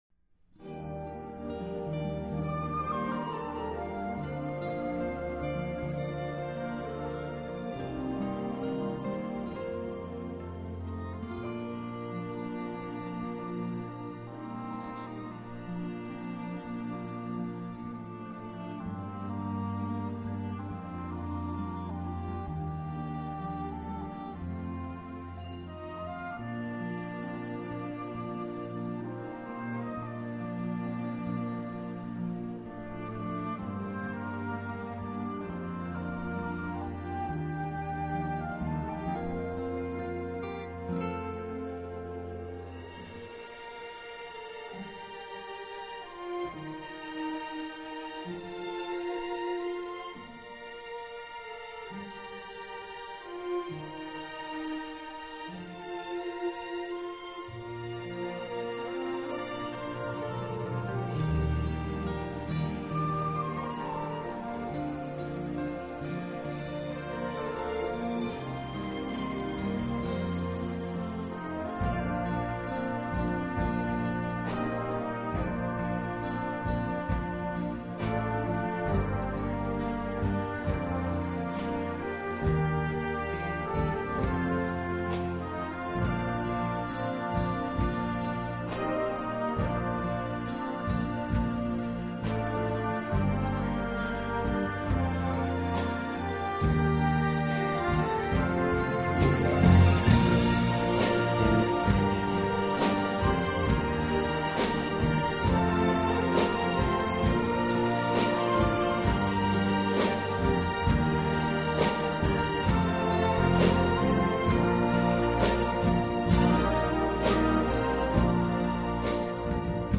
令声音的密度、音色、音场的表现相当出色。